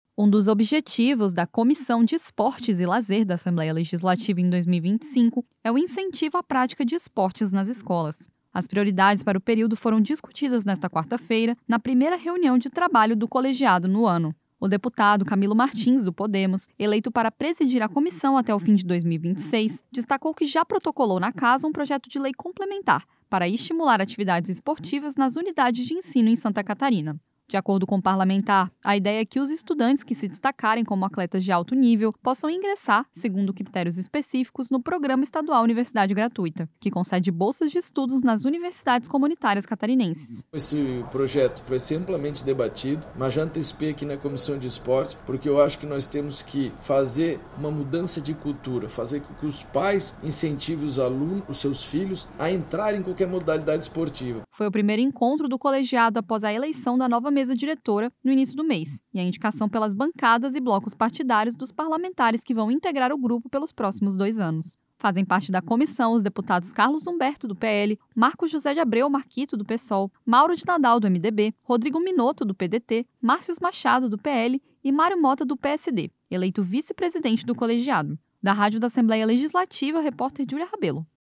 Entrevista com:
- deputado Camilo Martins (Podemos), presidente da Comissão de Esportes e Lazer da Assembleia Legislativa.